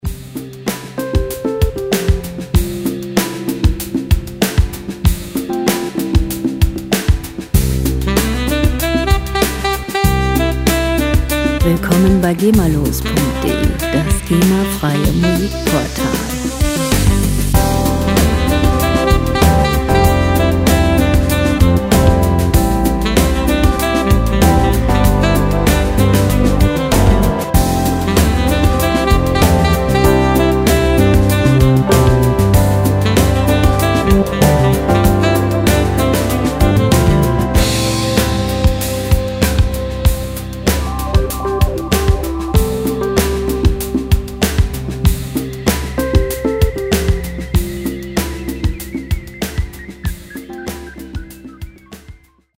Musikstil: Fusion
Tempo: 96 bpm
Tonart: C-Dur
Charakter: entspannend, auffordernd